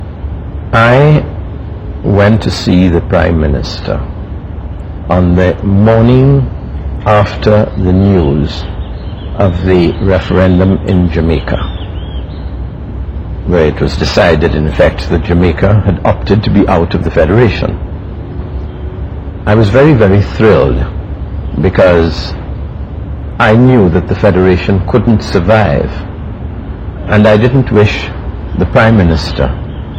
Sir Ellis Clarke was President of the Republic of Trinidad and Tobago at the time when this interview was done.
4 audio cassettes and 2 video cassettes